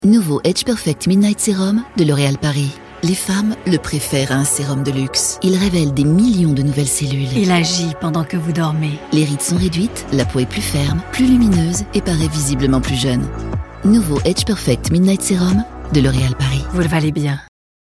chaleureuse